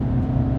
Звуки двигателя автомобиля
Рев мотора ускоряющейся машины